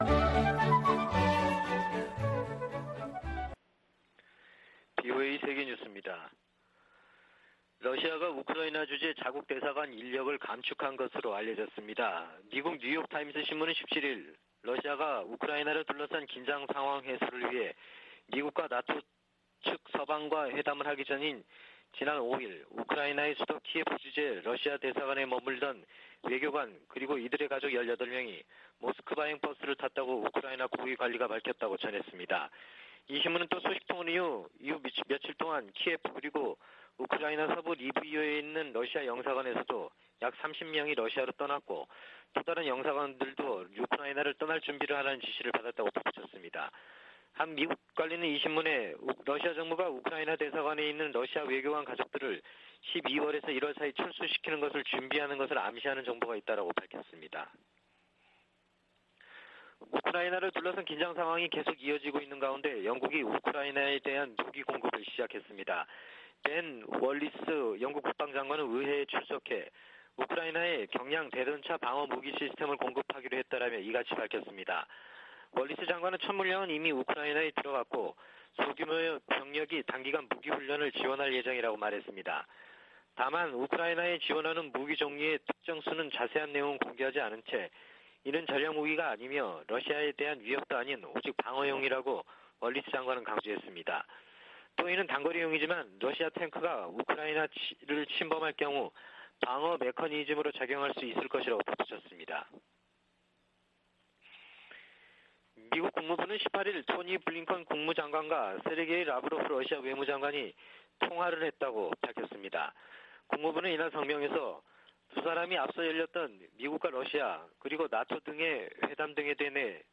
VOA 한국어 아침 뉴스 프로그램 '워싱턴 뉴스 광장' 2021년 1월 19일 방송입니다. 북한이 17일 쏜 발사체는 ‘북한판 에이태킴스’인 것으로 파악됐습니다.